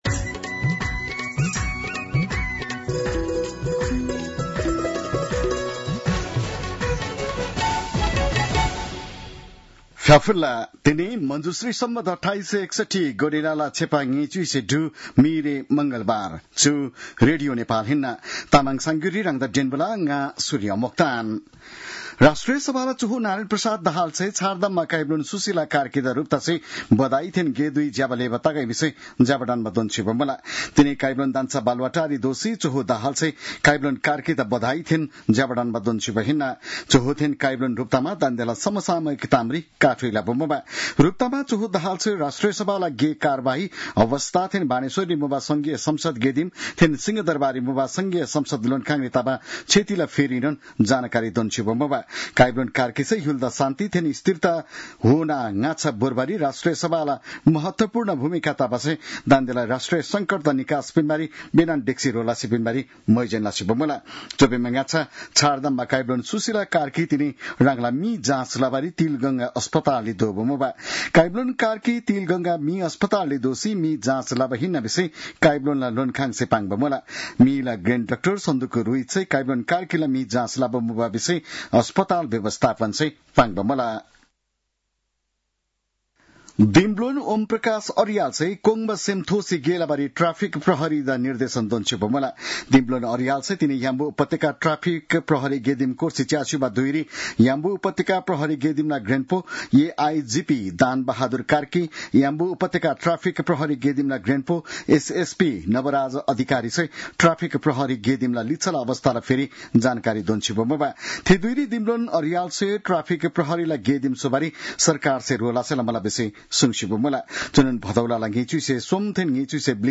An online outlet of Nepal's national radio broadcaster
तामाङ भाषाको समाचार : ३१ भदौ , २०८२